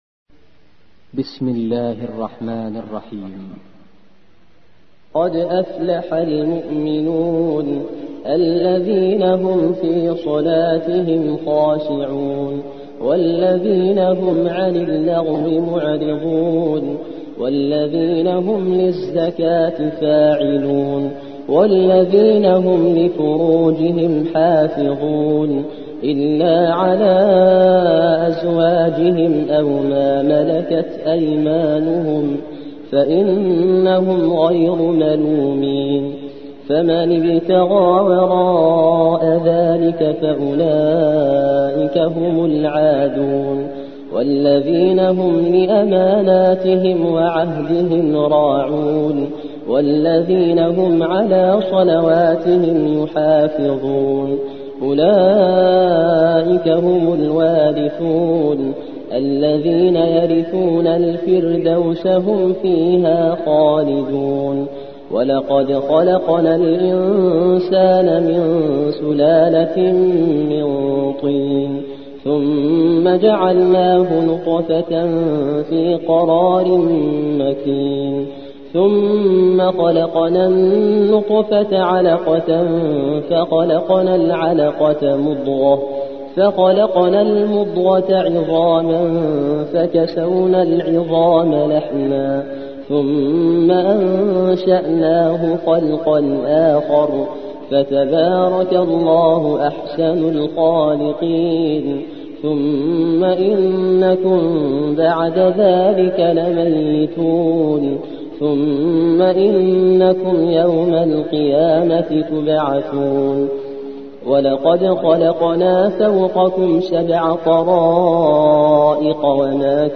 23. سورة المؤمنون / القارئ